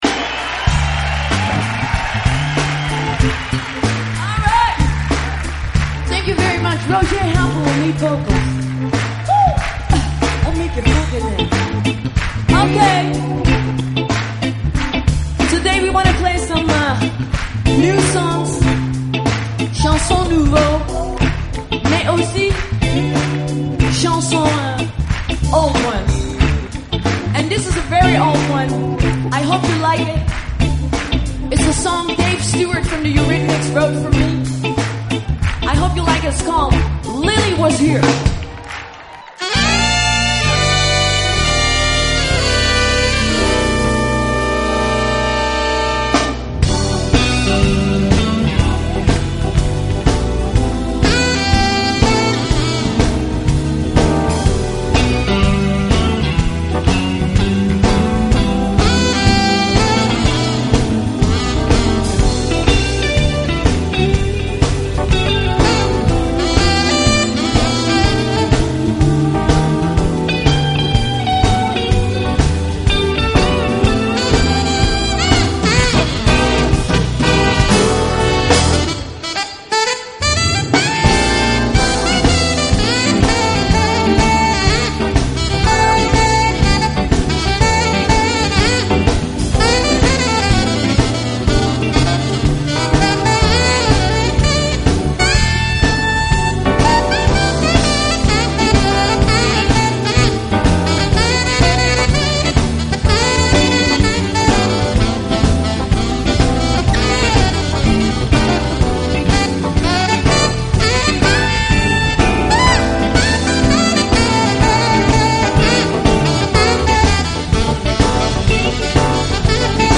面庞，她吹奏中音萨克斯的技巧也堪称一流。